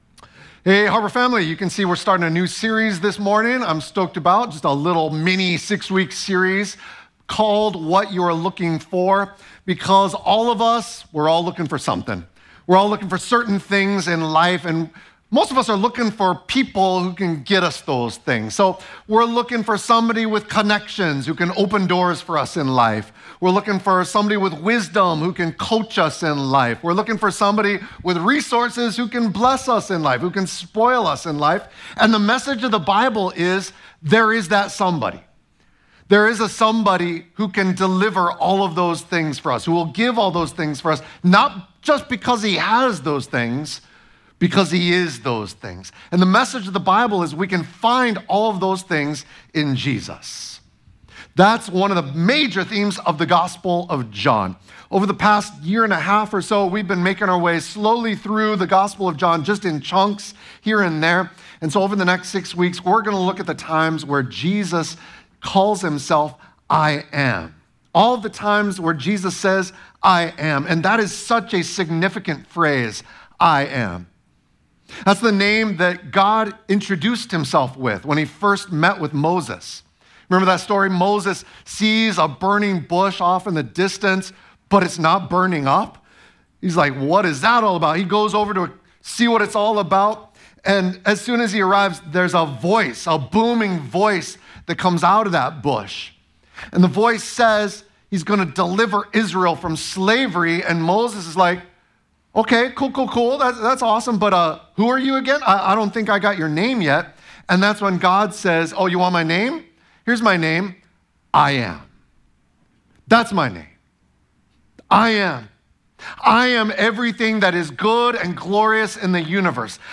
Sermon Audio from Harbor Church Honolulu